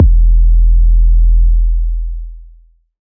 E-EDMBass-3.wav